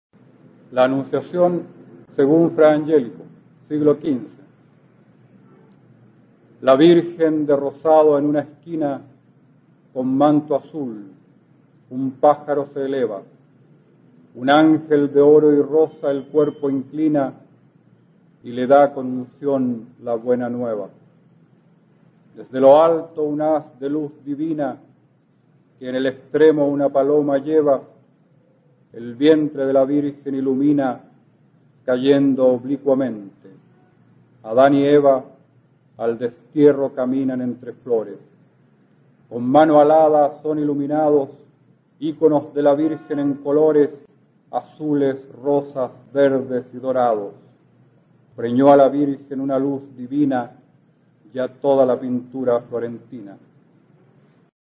Aquí podrás escuchar al poeta chileno Óscar Hahn, perteneciente a la Generación del 60, recitando su soneto La Anunciación según Fra Angélico, del libro "Estrellas fijas en un cielo blanco" (1989).